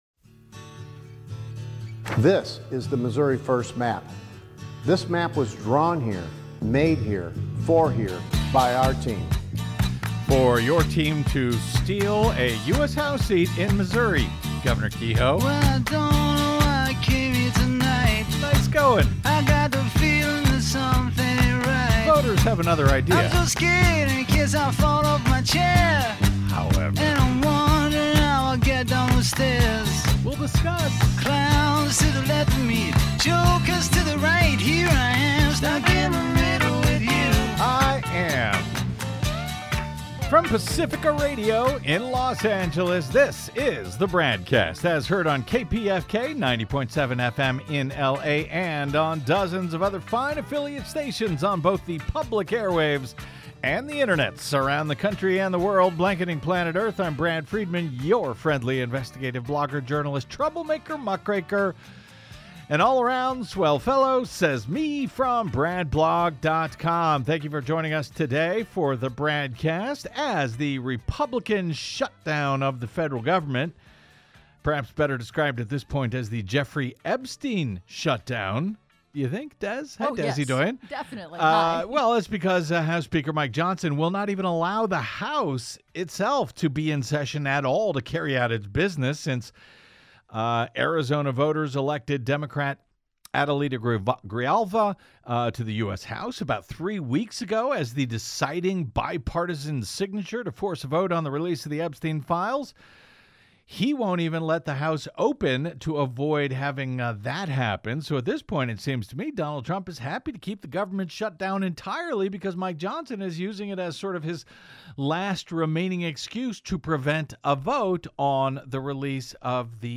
investigative interviews, analysis and commentary